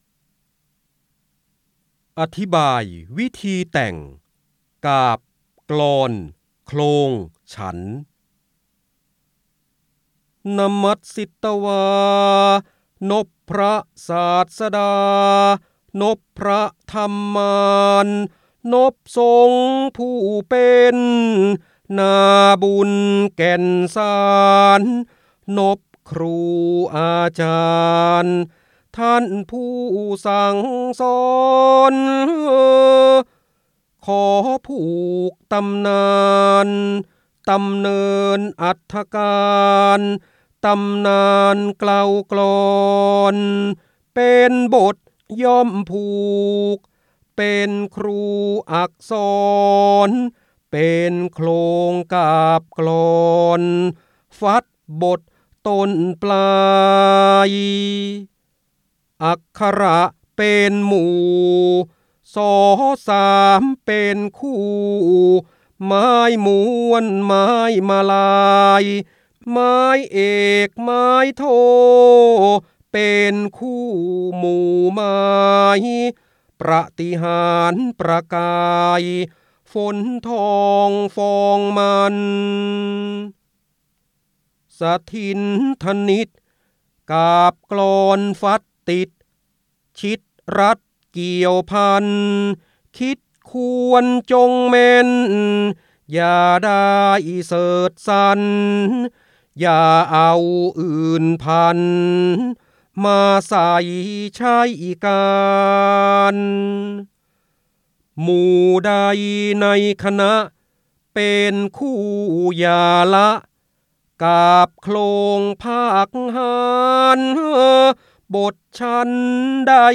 18 60 ดาวน์โหลด ส่ง eCard เสียงบรรยายจากหนังสือ จินดามณี (พระโหราธิบดี) อธิบายวิธีแต่ง กาพย์ กลอน โคลง ฉันท์ ได้รับใบอนุญาตภายใต้ ให้เผยแพร่-โดยต้องระบุที่มาแต่ห้ามดัดแปลงและห้ามใช้เพื่อการค้า 3.0 Thailand .